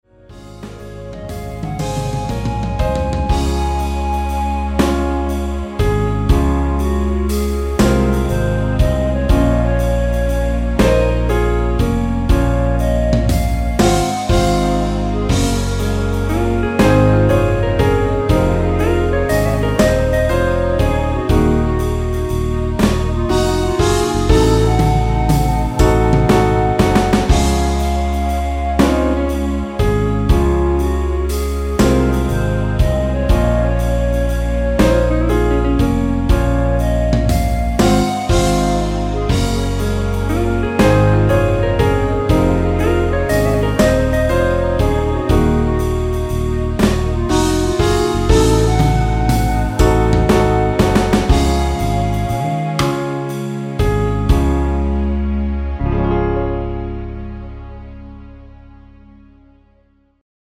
멜로디 포함된 MR입니다.
엔딩이 페이드 아웃이라 가사 끝 (널 사랑해) 까지 하고 엔딩을 만들었습니다.(가사및 미리듣기 참조)
Db
앞부분30초, 뒷부분30초씩 편집해서 올려 드리고 있습니다.
곡명 옆 (-1)은 반음 내림, (+1)은 반음 올림 입니다.
(멜로디 MR)은 가이드 멜로디가 포함된 MR 입니다.